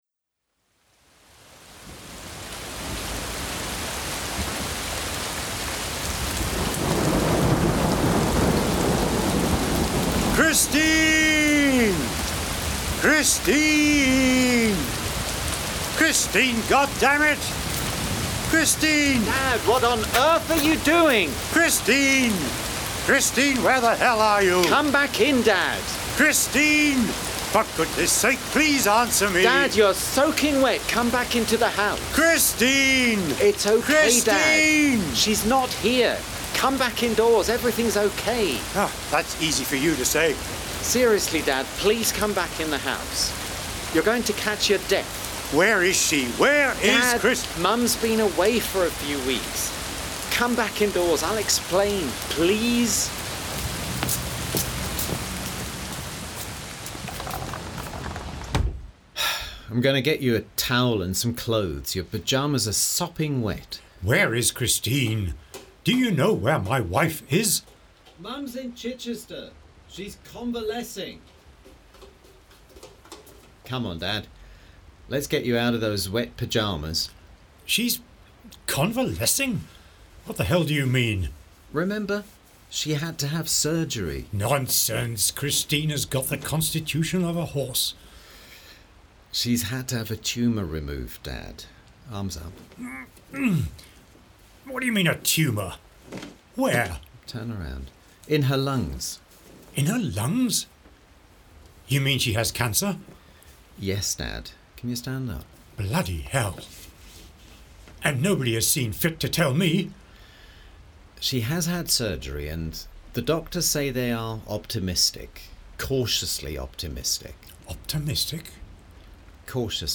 Award-winning radio play Minutes of an Extended Departure